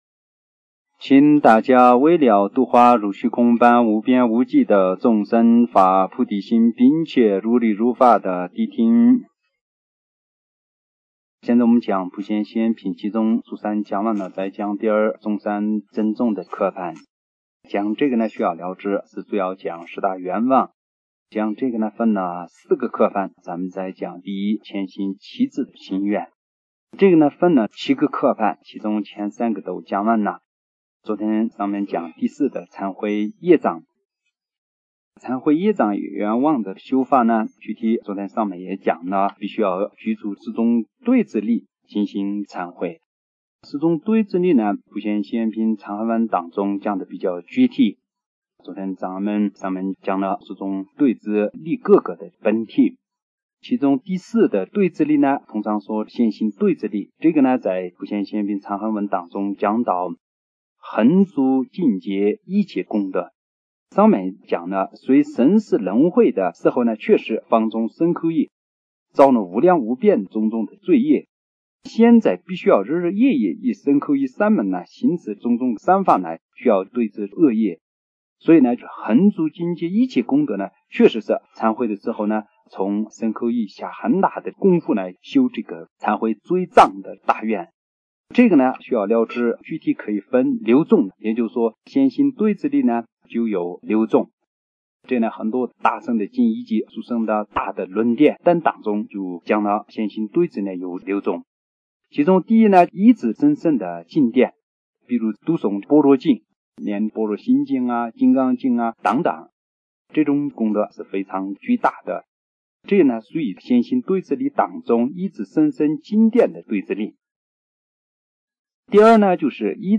佛學講座